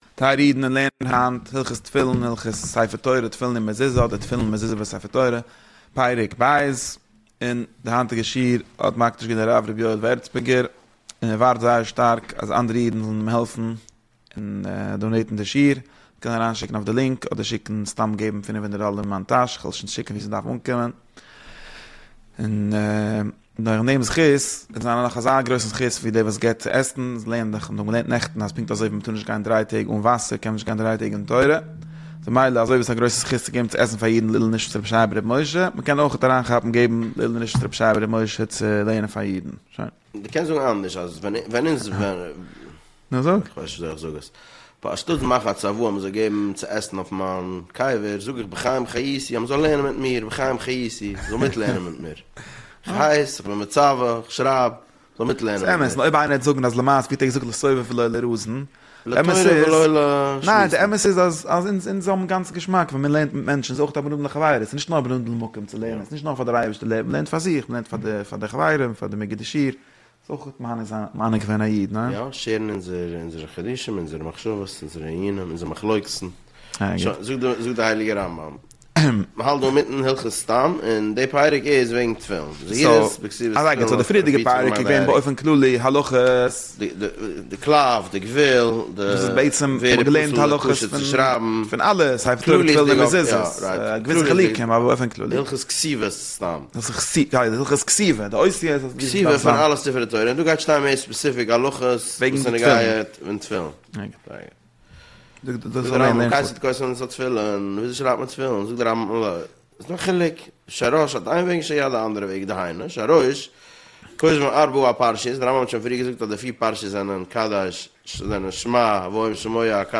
שיעורים על הרמב"ם פרק אחד ליום